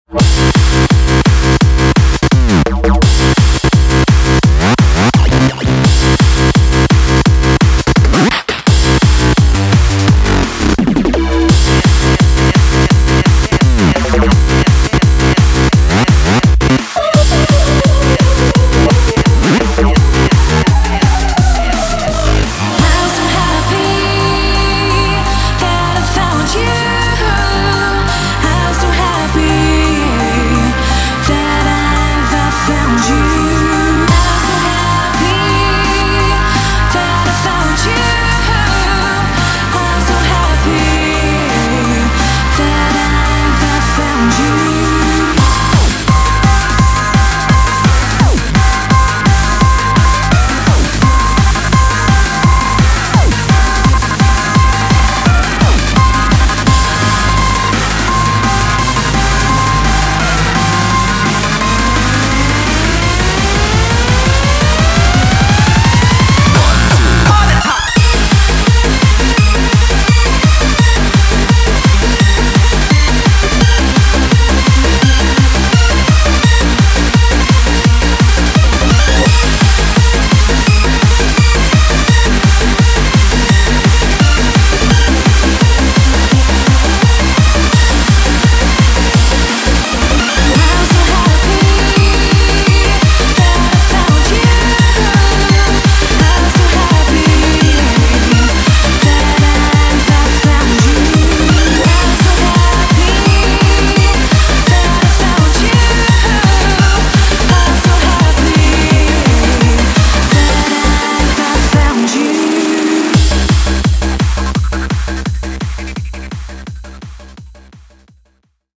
BPM120-170
Audio QualityMusic Cut